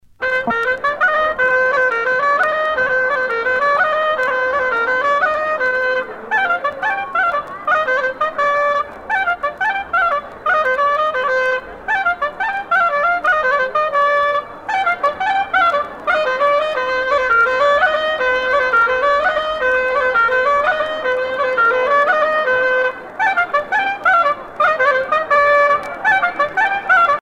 Trémargat
Bretagne
danse : plinn
Pièce musicale éditée